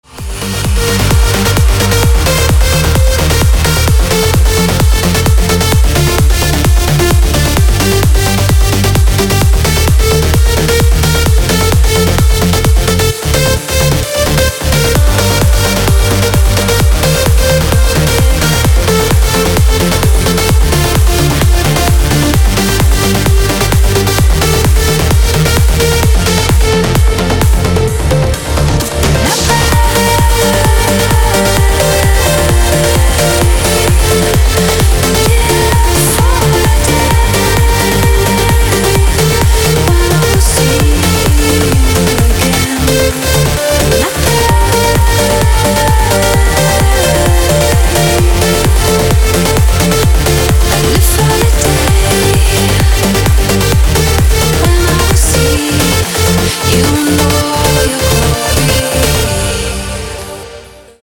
Клубные рингтоны